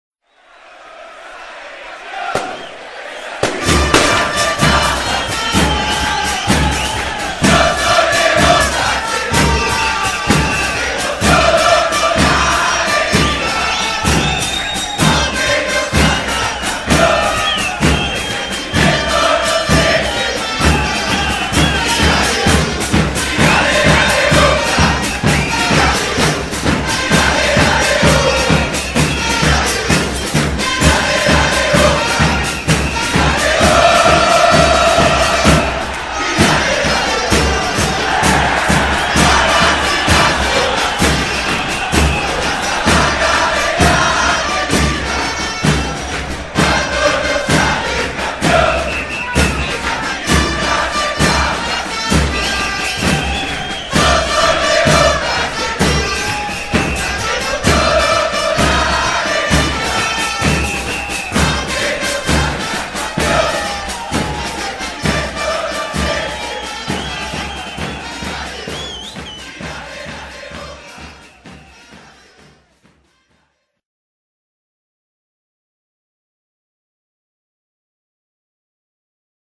Zde si můžete stáhnout 4 chorály Bocy (3 z nich natočeny přímo v kotli La Doce u kapely):
Chorál 1 (1,82 MB)